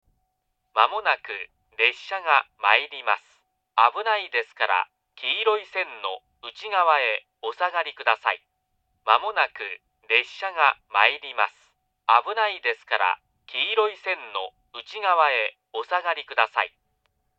２番線接近放送